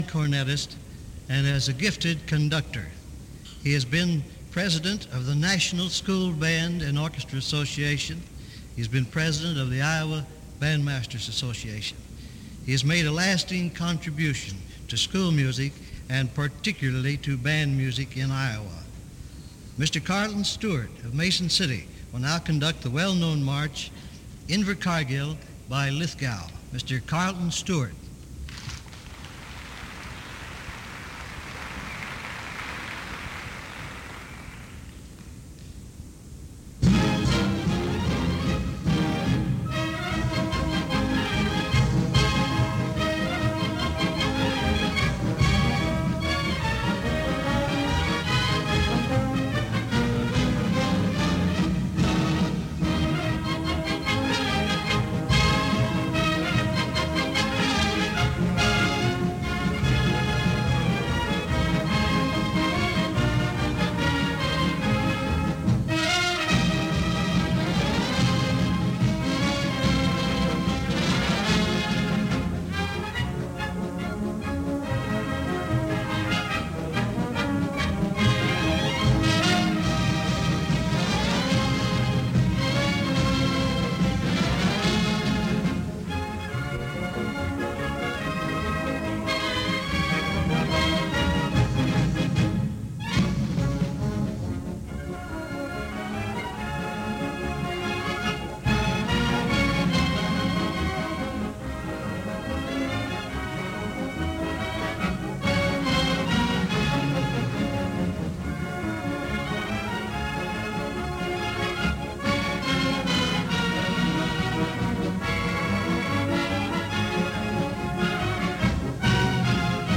These mp3 files are from a CD that was a digital transfer from a reel-to-reel tape. It is a recording of radio WHO’s broadcast of the Iowa Bandmasters Association “Directors Band” that played at the 1951 convention in Des Moines.